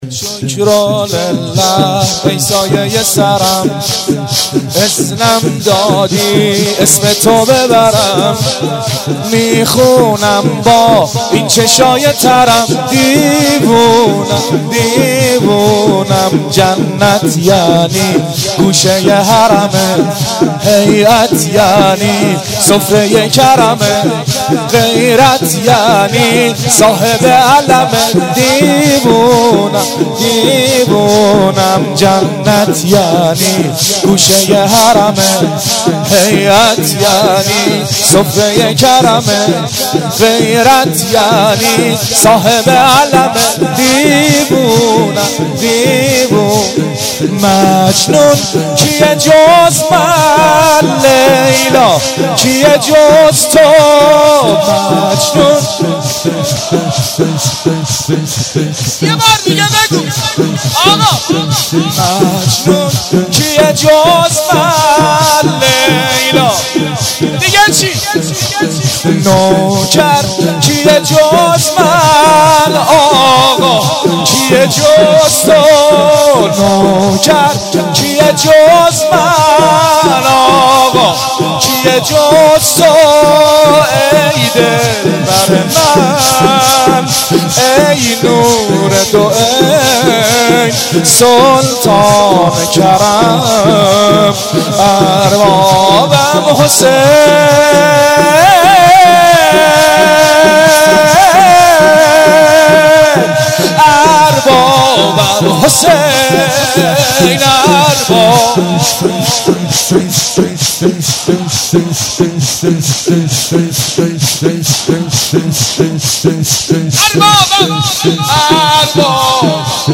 نوحه
شور
جلسه هفتگی